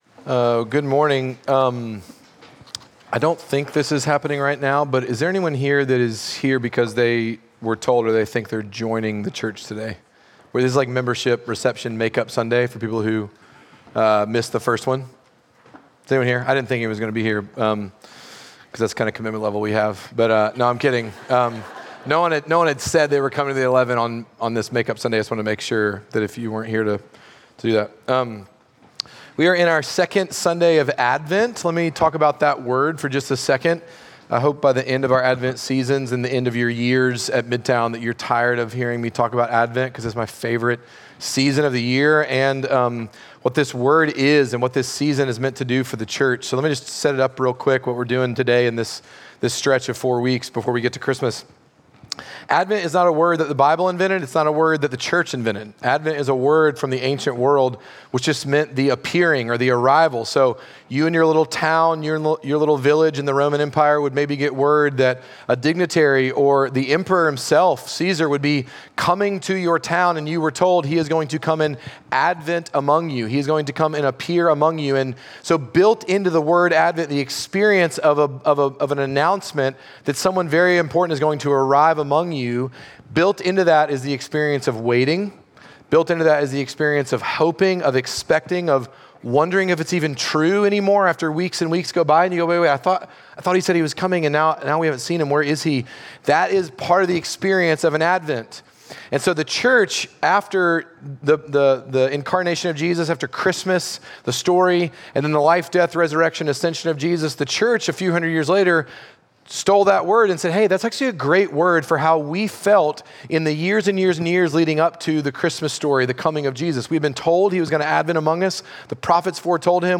Midtown Fellowship 12 South Sermons Peace In The Badlands Dec 08 2024 | 00:44:05 1x 00:00 / 00:44:05 Subscribe Share Apple Podcasts Spotify Overcast RSS Feed Share Link Embed